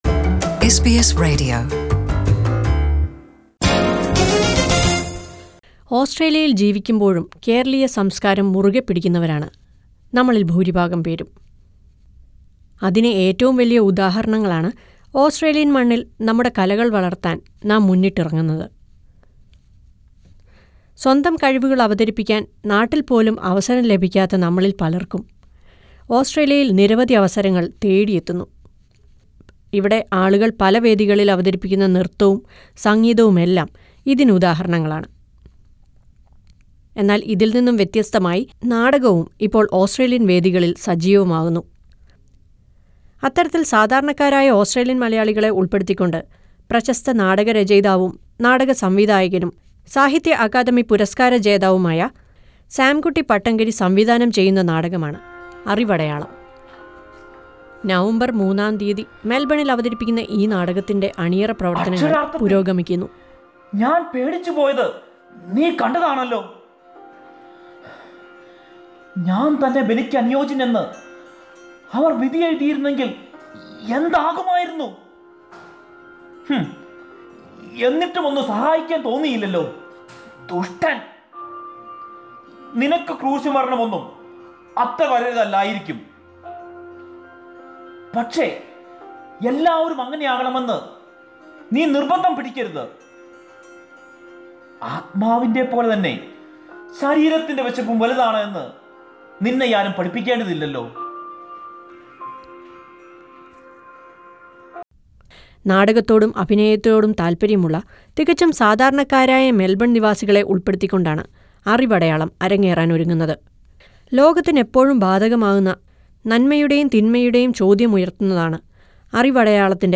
Listen to a report on the drama 'Árivadayalam' which is getting ready to be staged in Melbourne.